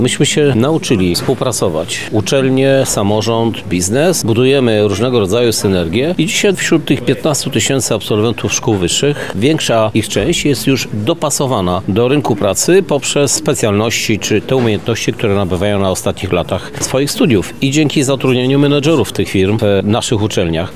Dziś (12 marca) odbyła się konferencja pt. „Technologiczne i społeczne wyzwania robotyki w świetle paradygmatów Przemysłu 5.0”.
Krzysztof Żuk– mówi Prezydent Lublina, dr Krzysztof Żuk